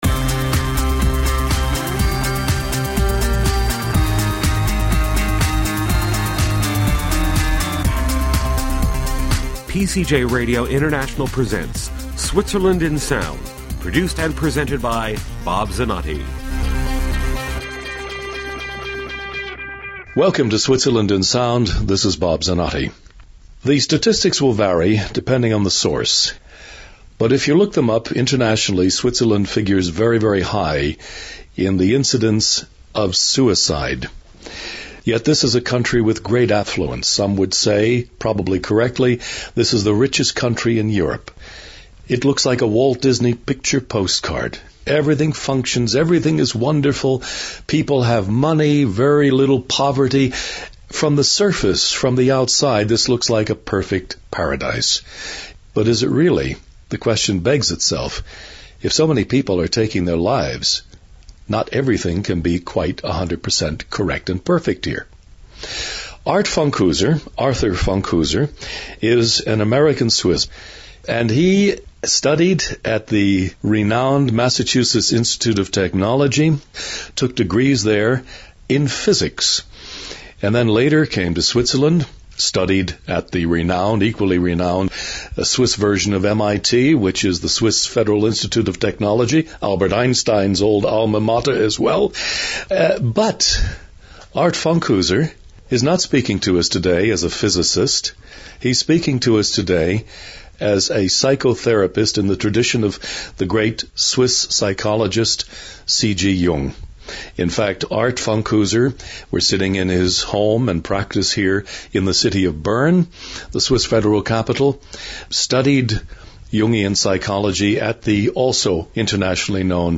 Program Type: Interview